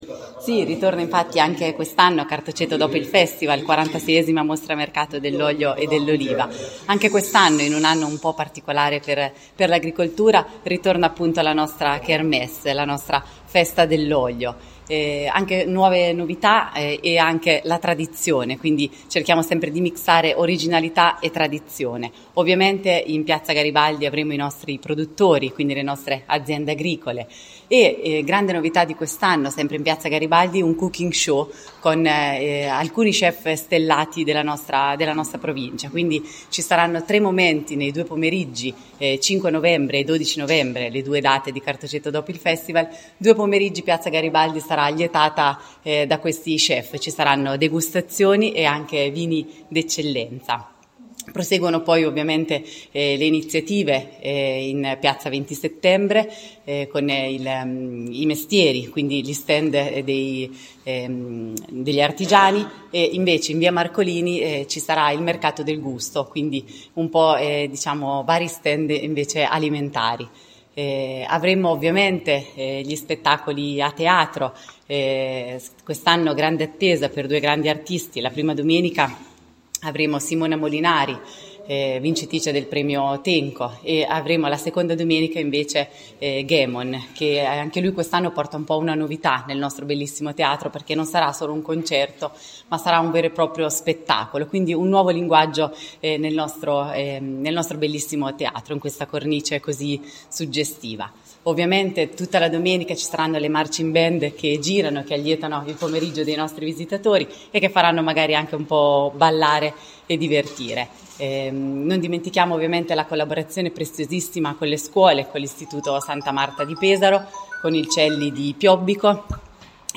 Cartoceto-dop-conferenza.mp3